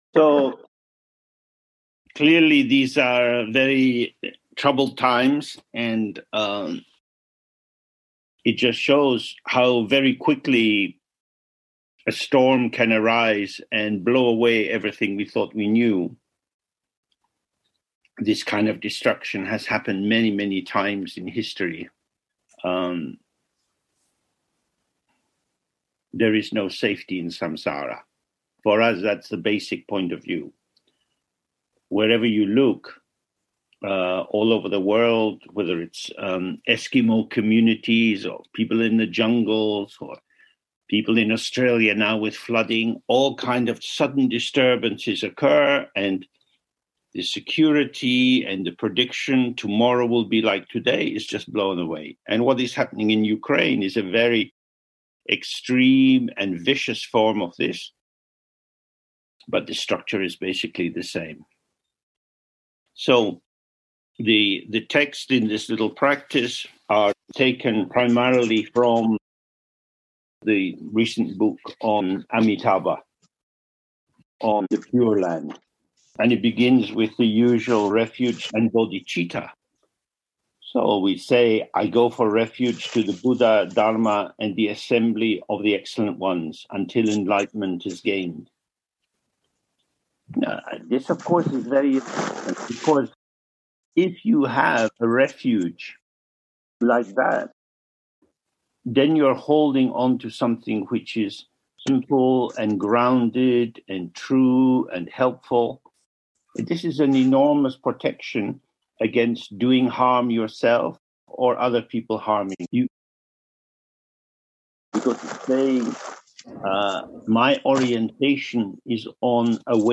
So if you like to use audio recordings for study it’s now very listenable.
Repelling all Troubles Dok! Dokpa (Enhanced).mp3